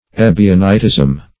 Search Result for " ebionitism" : The Collaborative International Dictionary of English v.0.48: Ebionitism \E"bi*o*ni`tism\, n. (Eccl.